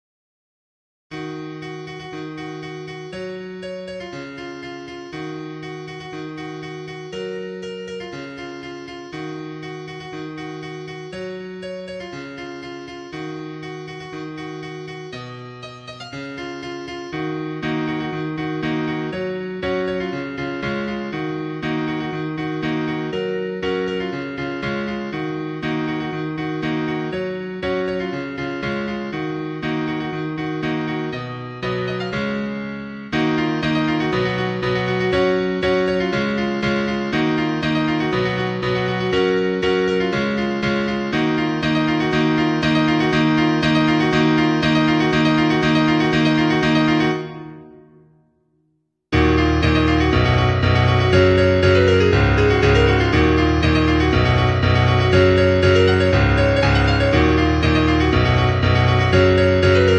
Nhạc EDM Cover Piano